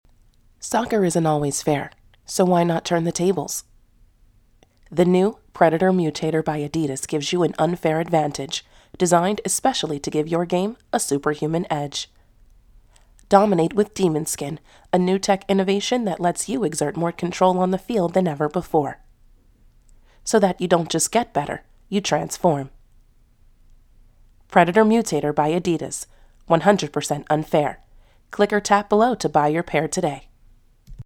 Female
Yng Adult (18-29), Adult (30-50)
A versatile, professional tone and wide range!
My voice is clear, crisp, and inviting.
My range varies from authoritative to soft.
All our voice actors have professional broadcast quality recording studios.